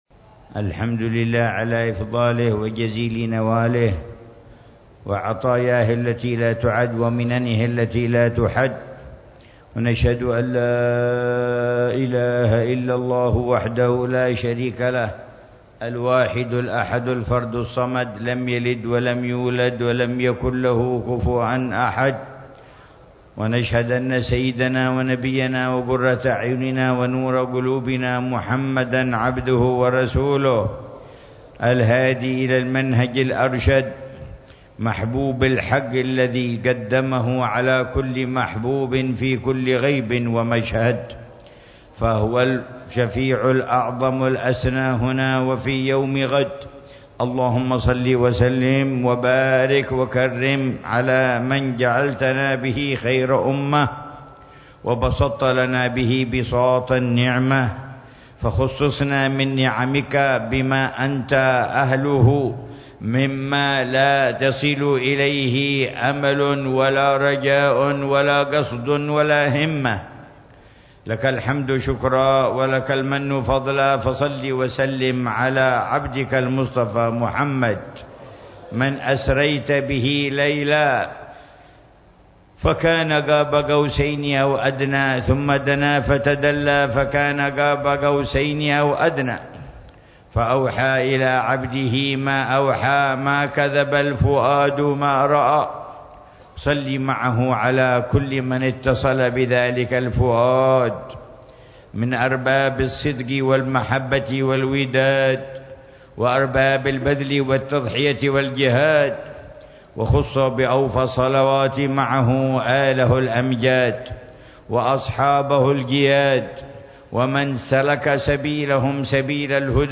كلمة في مجلس توديع طلاب الدورة التاسعة والعشرين بدار المصطفى بتريم
كلمة العلامة الحبيب عمر بن محمد بن حفيظ في مجلس توديع طلاب الدورة التعليمية التاسعة والعشرين بدار المصطفى بتريم صباح يوم السبت 25 محرم 1445هـ